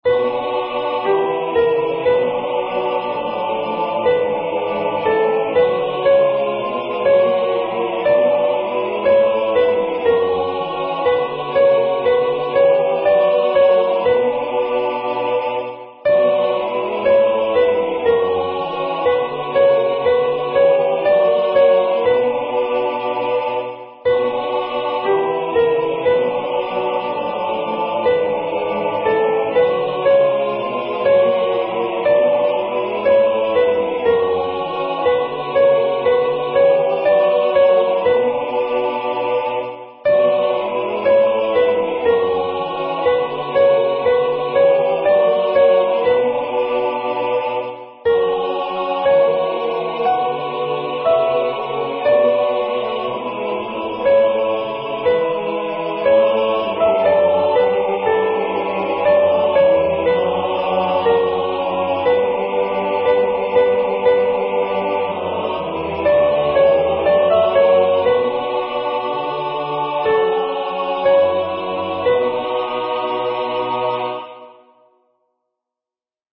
Practice Files:: Soprano:
Genre: SacredMass
Mb_LambOfGodSoprP.mp3